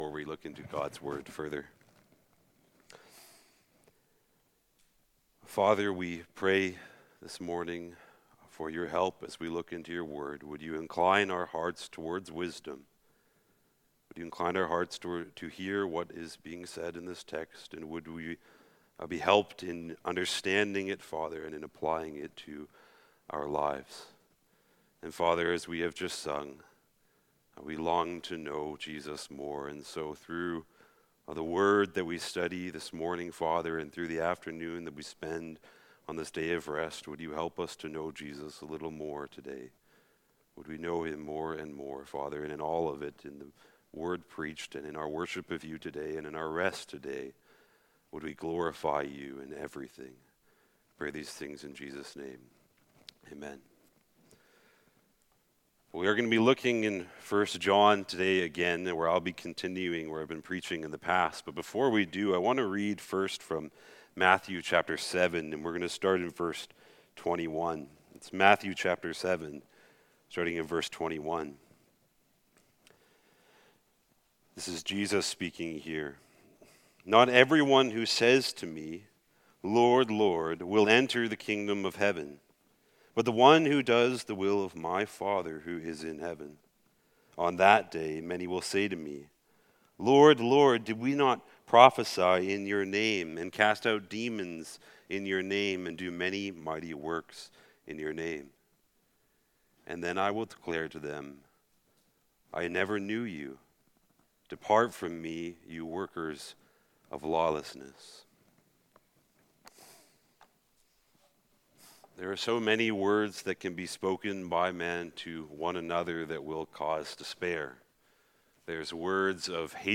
Sermons - Christ Community Church